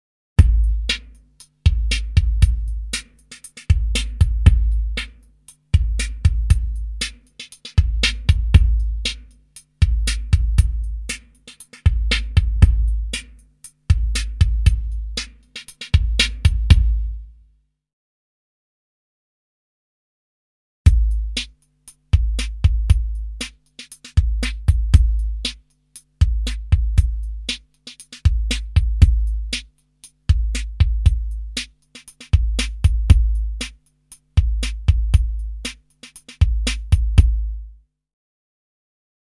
3D spatial surround sound "Drums"
3D Spatial Sounds